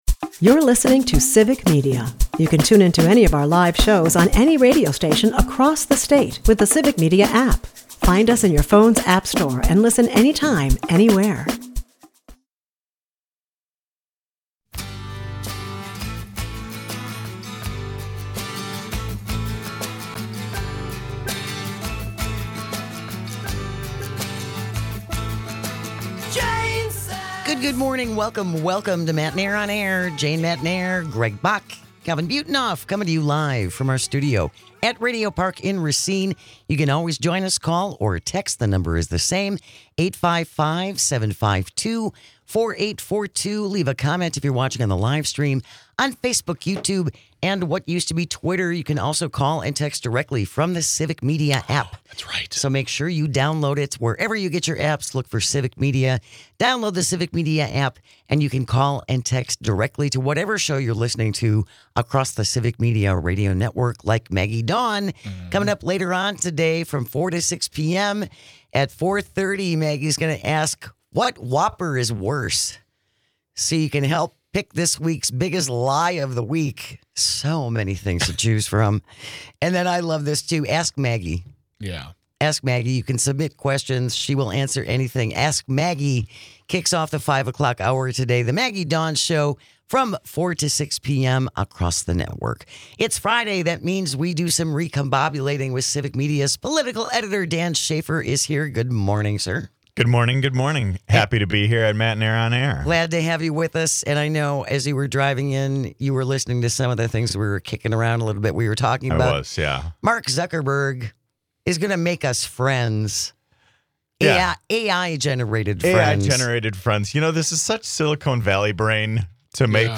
Matenaer On Air is a part of the Civic Media radio network and airs Monday through Friday from 9 -11 am across the state.